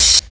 112 OP HAT.wav